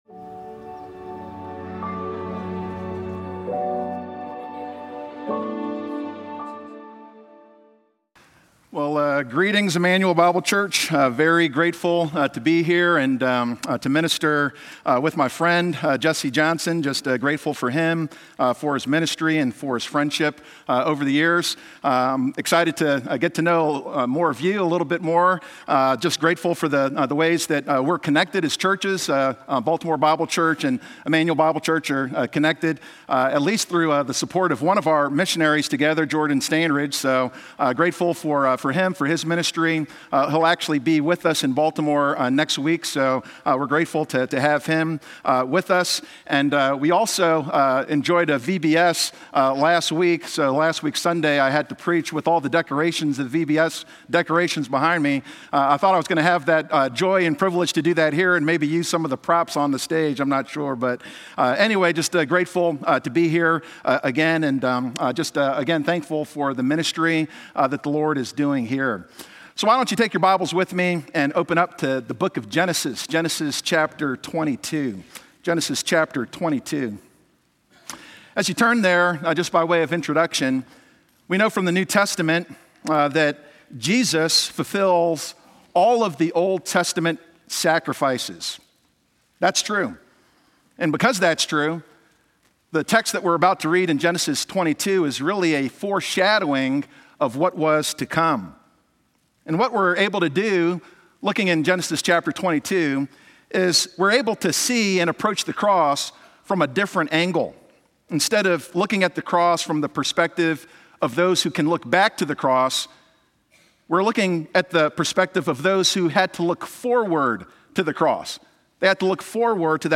Sunday Morning Worship Service
Guest Speaker